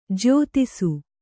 ♪ jyōtisu